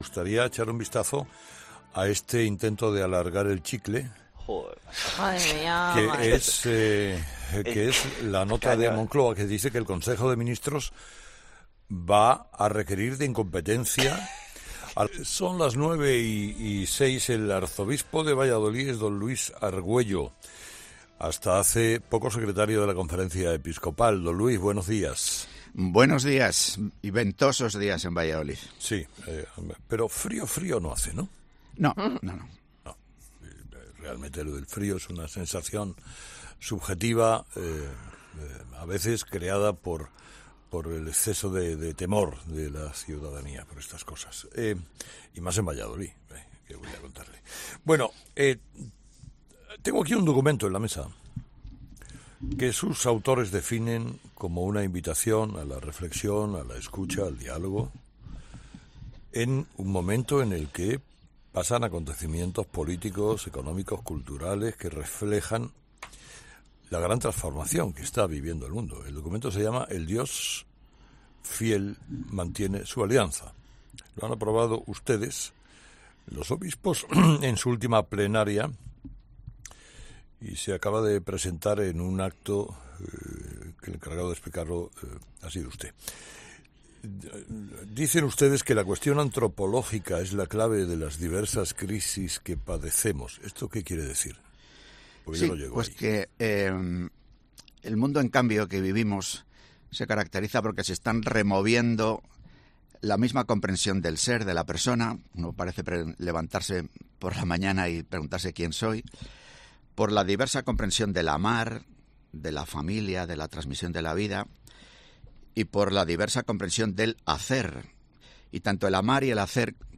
Carlos Herrera ha entrevistado este martes a las 9 de la mañana al arzobispo de Valladolid, Luis Argüello, para hablar del documento de la Conferencia Episcopal sobre persona, familia y sociedad ‘El Dios fiel mantiene su alianza’. Otro de los asuntos tratados han sido obviamente las medidas de apoyo a las mujeres embarazadas en Castilla y León.